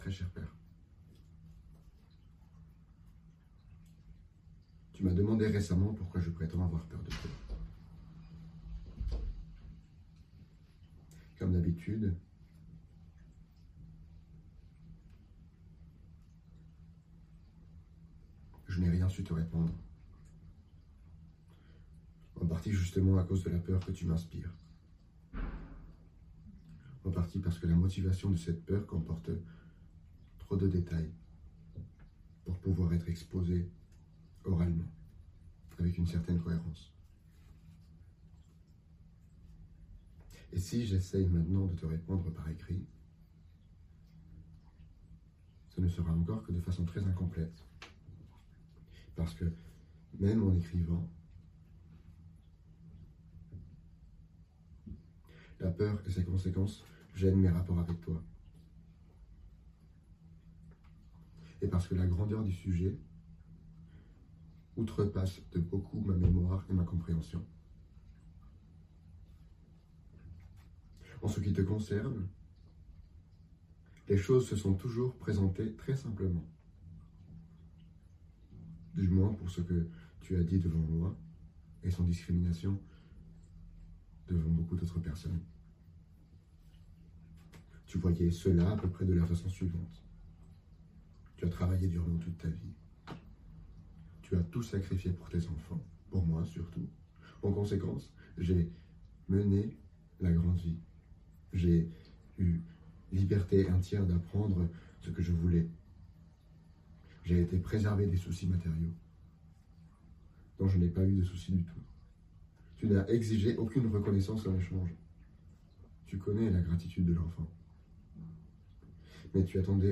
Voix off
Lecture de lettre au père de Kafka
20 - 50 ans - Baryton